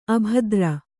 ♪ abhadra